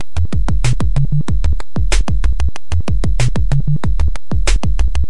描述：123bpm FX + Bass
标签： 节奏123bpm 运营商 鼓环 PO-12 工程 打击乐循环 扭曲 价格便宜 MXR 击败了 周一 十几岁 口袋
声道立体声